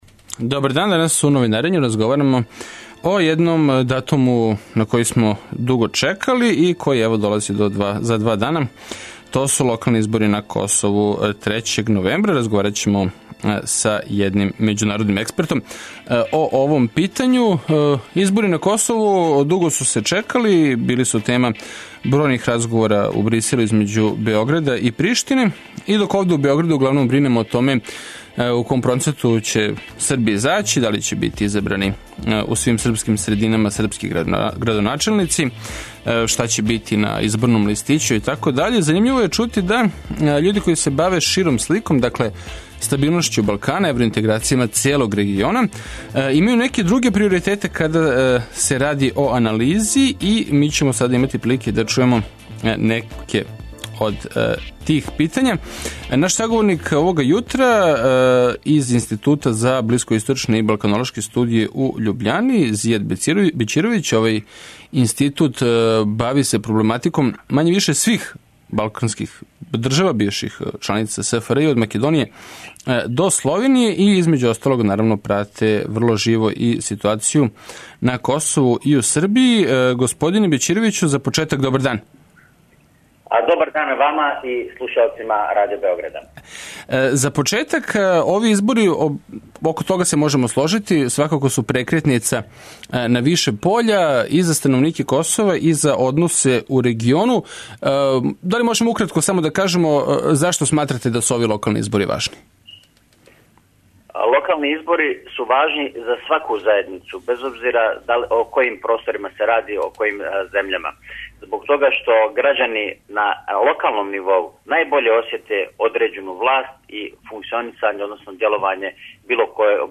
[ детаљније ] Све епизоде серијала Аудио подкаст Радио Београд 1 Ромска права у фокусу Брисела Хумористичка емисија Хумористичка емисија Хумористичка емисија Спортско вече, фудбал: Црвена Звезда - Партизан, пренос